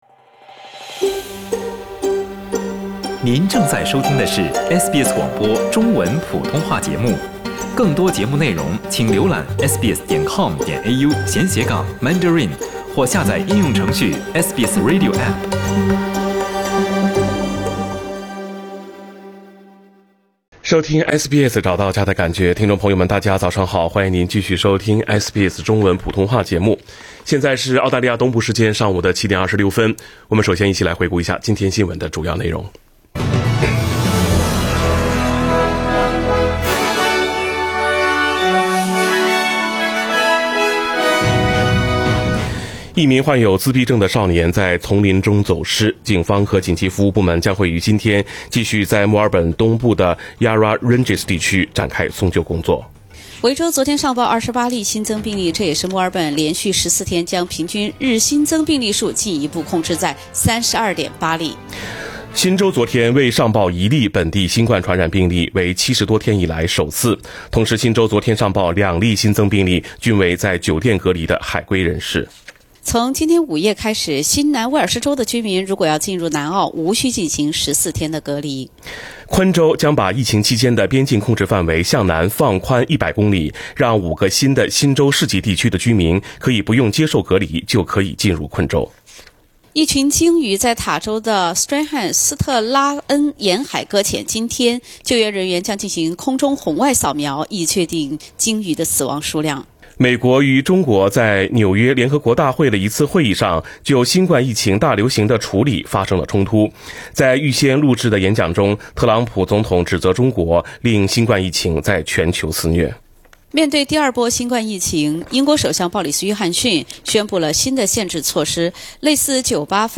SBS早新闻（9月23日）